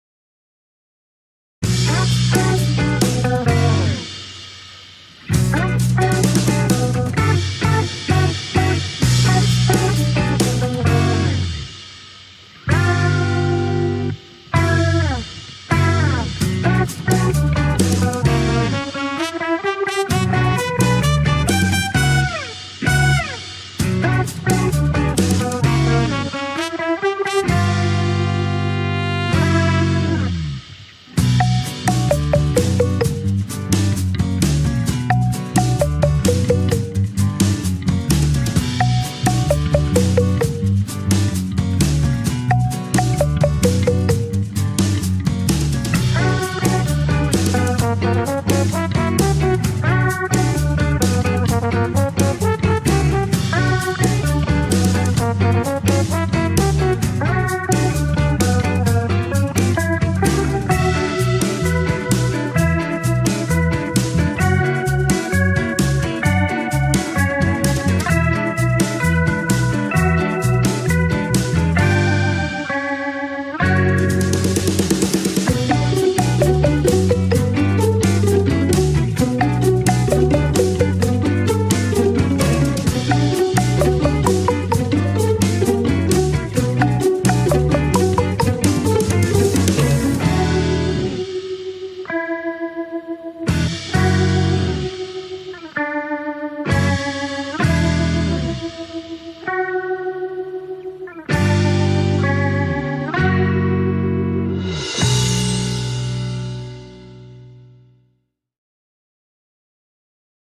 tema dizi müziği, neşeli eğlenceli enerjik fon müziği.